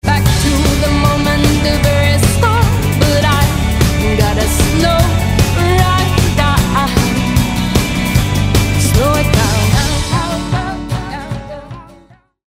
GenrePoprock